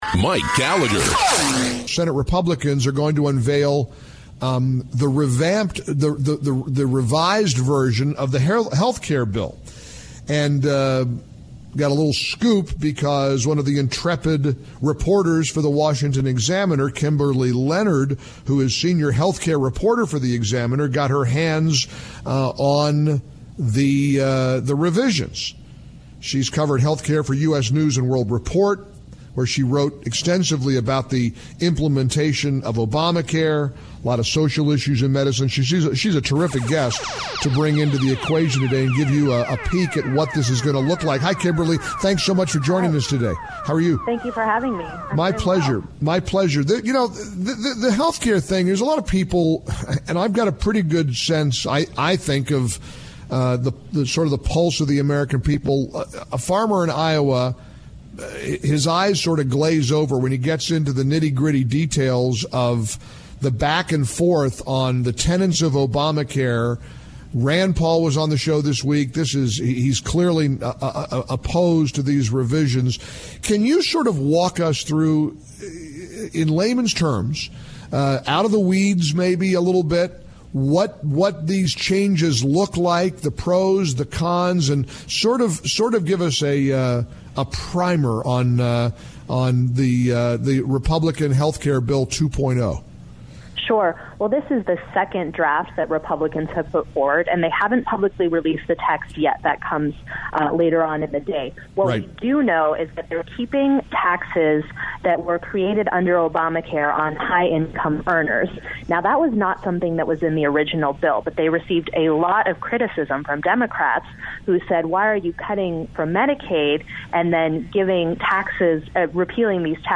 A blend of timely political commentary, compelling talk and terrific discussions on social issues and lifestyle topics.